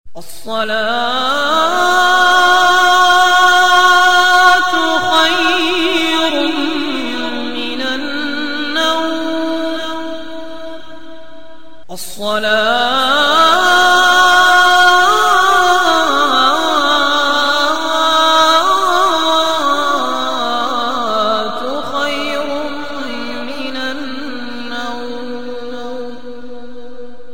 Descarga de Sonidos mp3 Gratis: fajar alarm.
ringtones-fajar-alarm.mp3